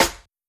Medicated Snare 13.wav